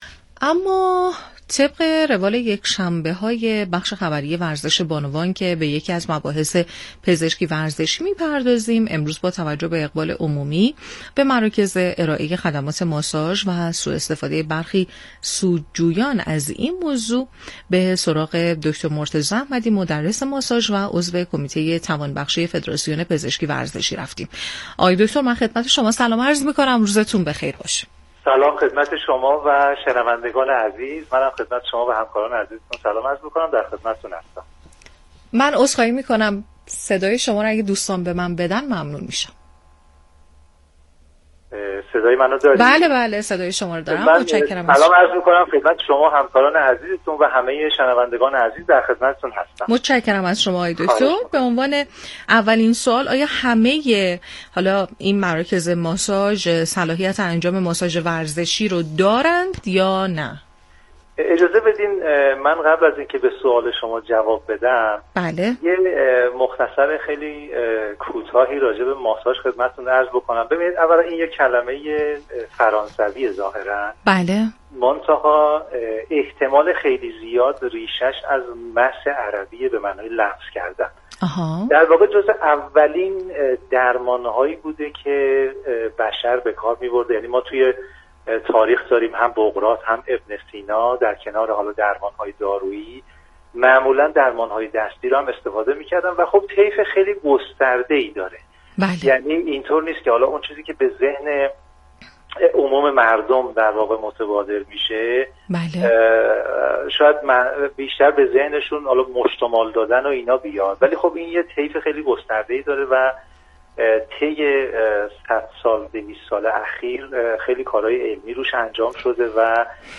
1. خبر ستادی